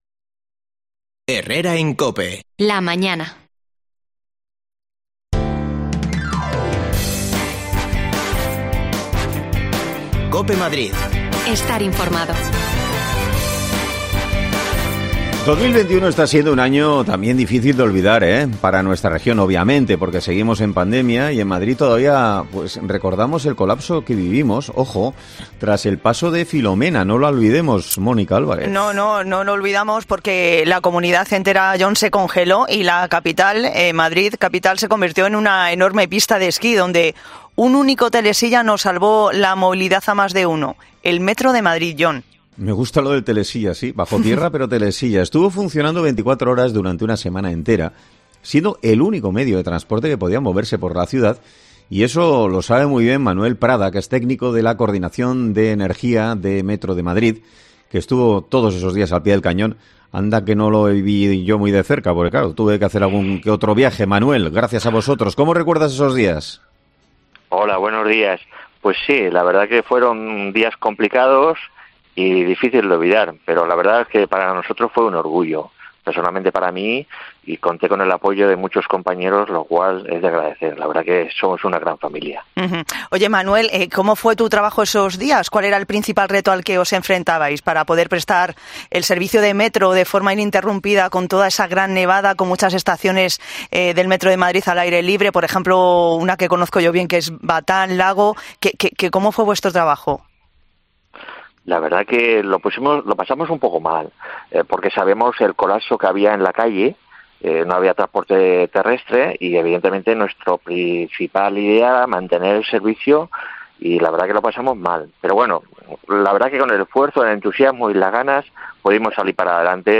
Hoy ha sido premiado por su trabajo. Hablamos con él